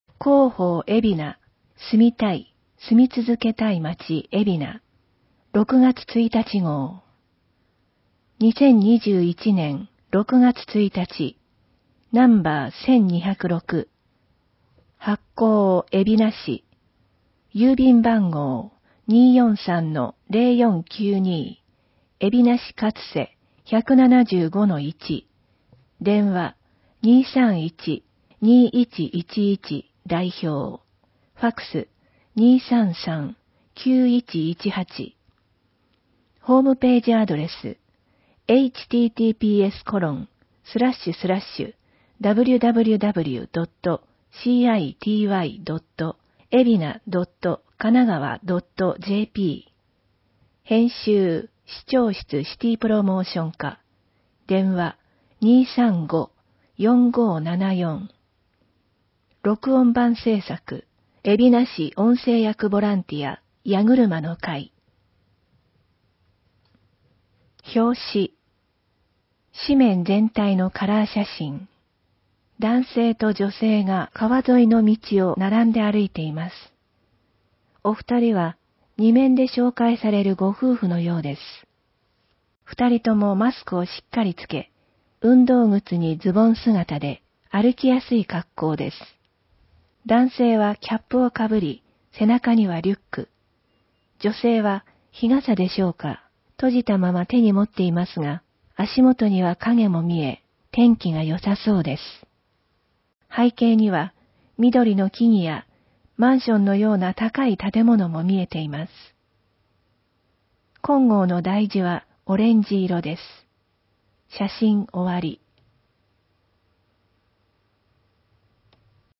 広報えびな 令和3年6月1日号（電子ブック） （外部リンク） PDF・音声版 ※音声版は、音声訳ボランティア「矢ぐるまの会」の協力により、同会が視覚障がい者の方のために作成したものを登載しています。
広報えびな 1～16面 （PDF 3.2MB） 表紙 令和3年6月1日号 表紙PDF版 （PDF 266.3KB） 表紙音声版 （mp3 349.1KB） 2面・3面 ポイントためて楽しく健康！